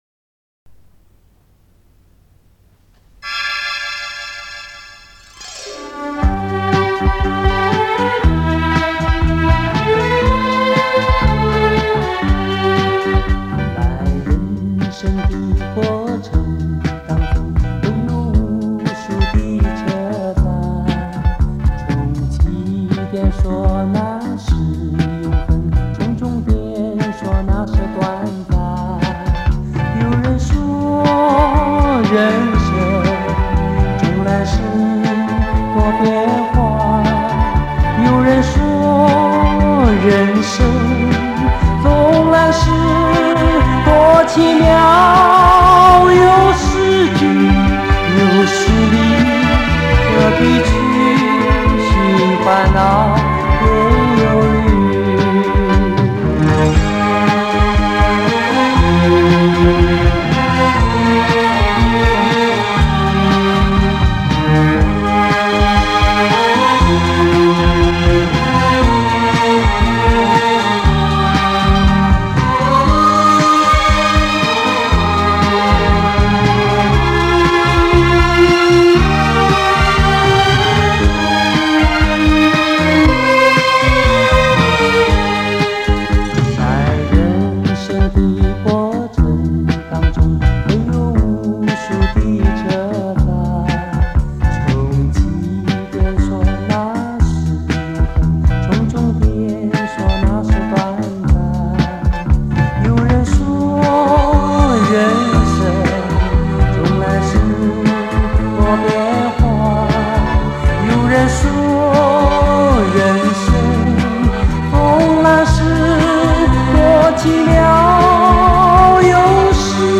24声道电脑控制录音
他的演唱热情、潇洒，音色深厚youli，具有明快的节奏感和艺术魅力。